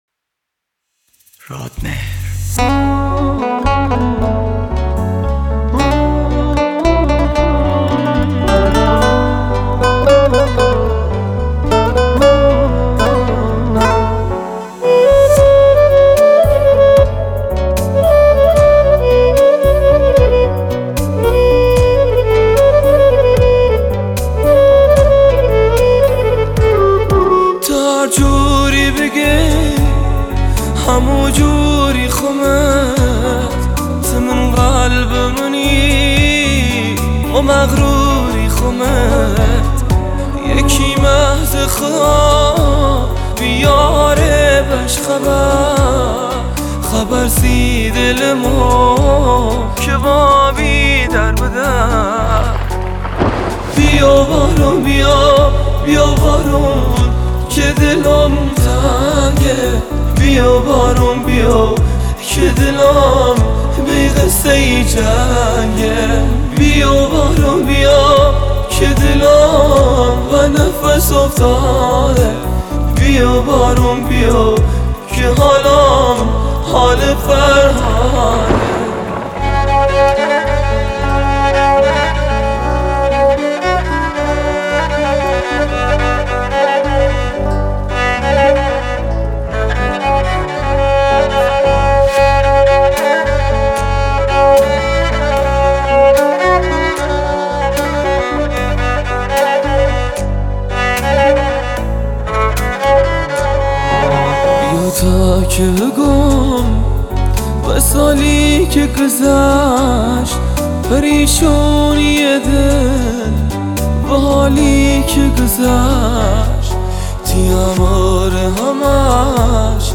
آهنگ لری
آهنگ غمگین و گریه آور